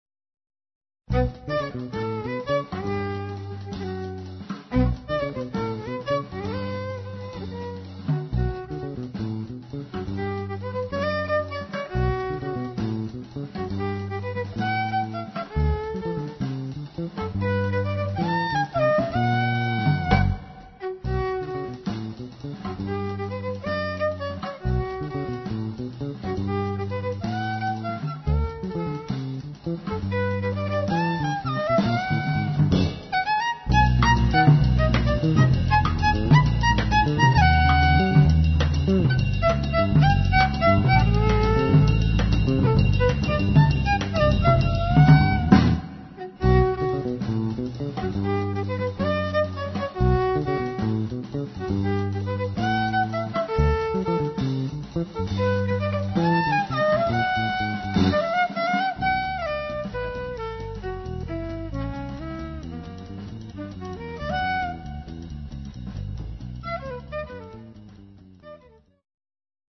violin, percussion, guitar, fluguelhorn, vocals
elettric bass
drums, surdo